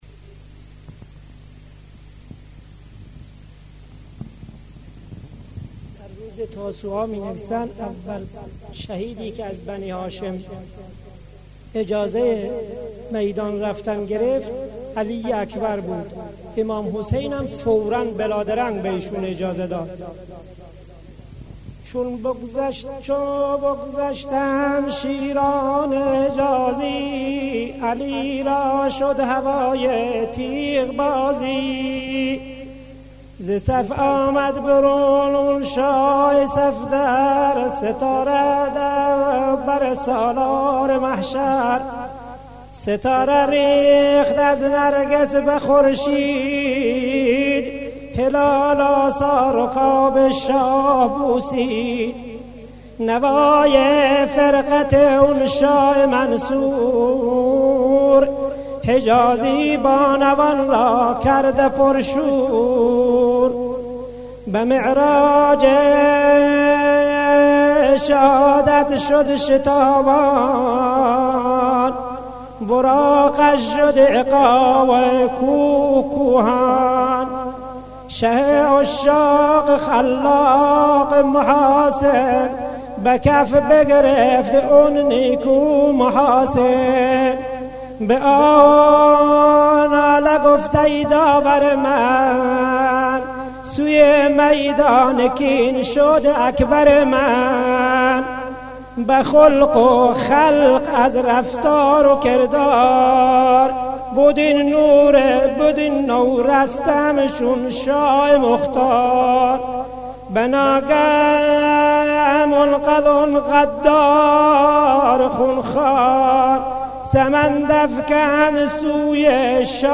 تعزیه علی اکبر در شب تاسوعا
بخشی از تعزیه علی اکبر در شب تاسوعا
tasooaa-taziye-aliakbar.mp3